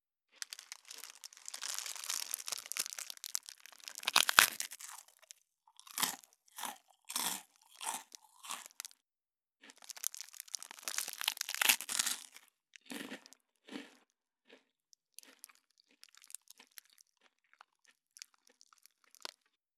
13.スナック菓子・咀嚼音【無料効果音】
ASMR